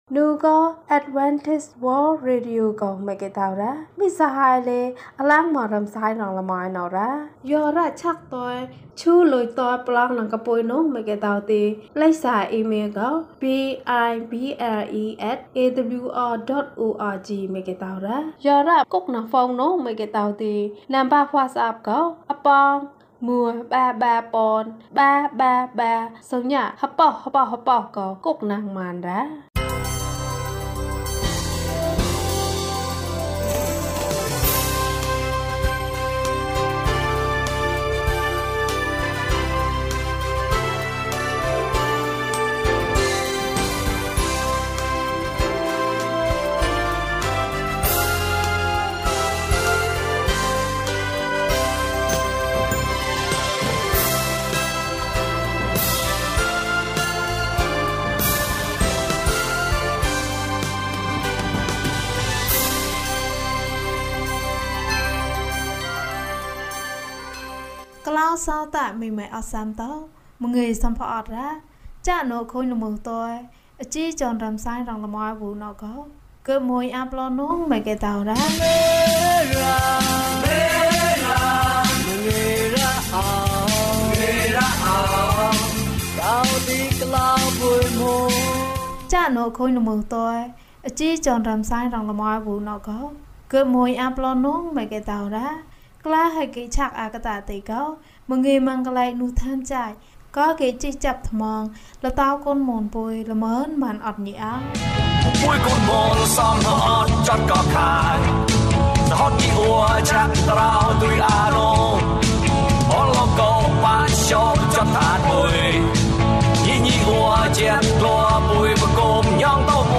သမ္မာကျမ်းစာဇာတ်လမ်း။ အပိုင်း၁ ကျန်းမာခြင်းအကြောင်းအရာ။ ဓမ္မသီချင်း။ တရားဒေသနာ။